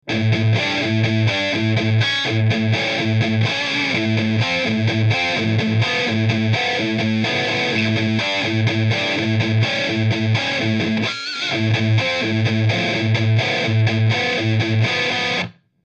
über Metal（金属）具有Metal、Pulverize和Insane三档选择，可以制造出类似LINE6的HD147及Vetta II等产生的一些高增益音色。
UM Metal.mp3